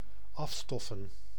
Ääntäminen
US
IPA : /ˈdʌst/